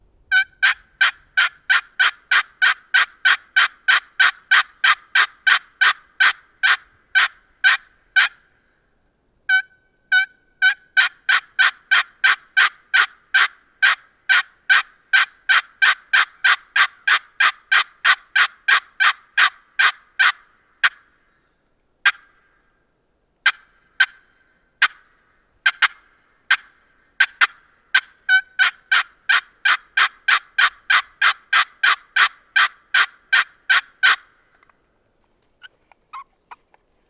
Snakewood over Curly Maple - High Class Calls